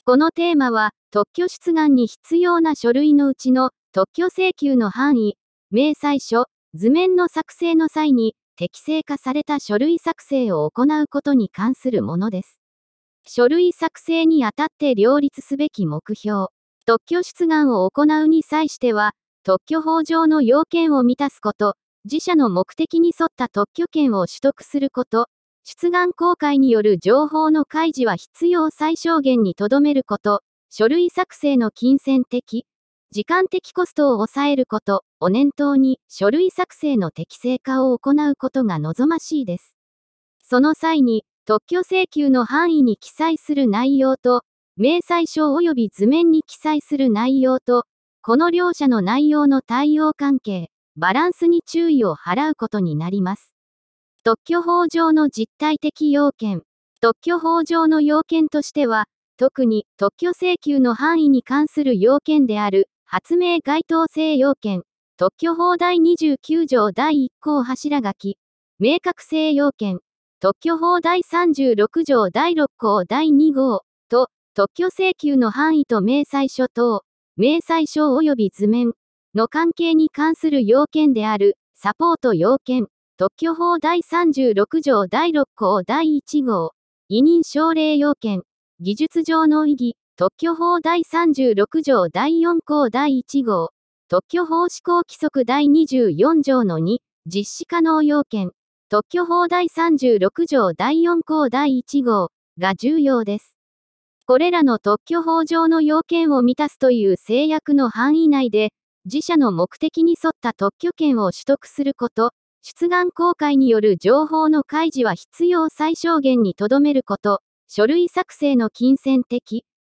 テーマの説明音声データ＞＞